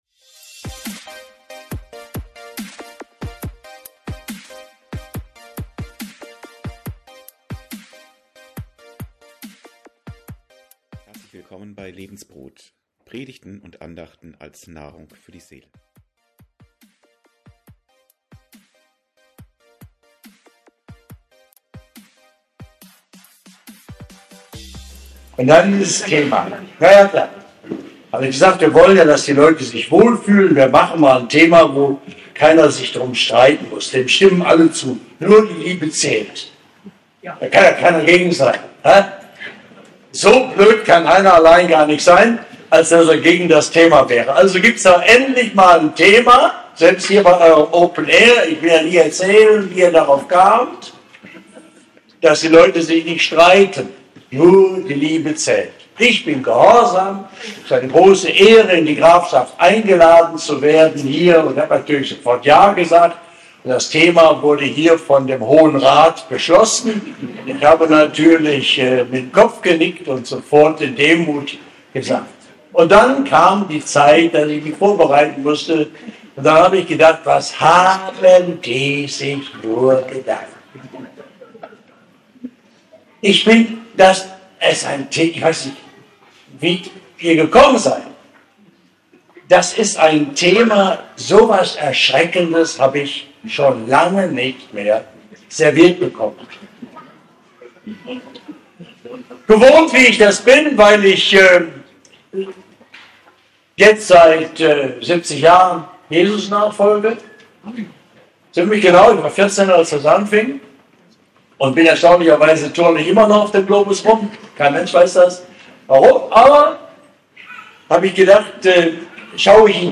Predigt
beim openair auf Hof Segger 2025